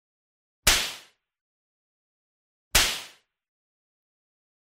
Tiếng Vụt, Đánh bằng thắt lưng da
Thể loại: Đánh nhau, vũ khí
Description: Tiếng quất thắt lưng, tiếng vút roi da, tiếng quật dây da, tiếng đánh roi, tiếng vụt dây da, âm thanh vút sắc gọn khi dây da quất mạnh trong không khí, tiếp theo là tiếng “chát” dội lại khi va vào bề mặt. Âm thanh mạnh mẽ, dứt khoát, thường dùng trong các cảnh phim hành động, võ thuật hoặc tái hiện bối cảnh kịch tính.
tieng-vut-danh-bang-that-lung-da-www_tiengdong_com.mp3